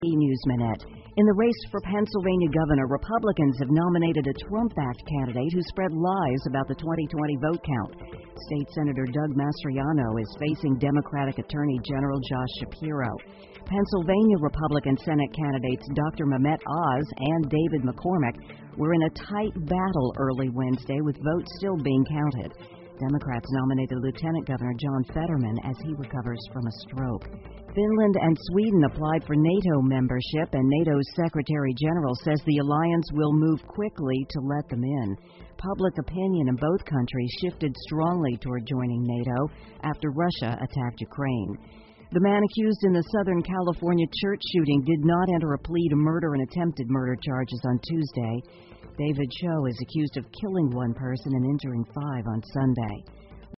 美联社新闻一分钟 AP 芬兰和瑞典申请加入北约 听力文件下载—在线英语听力室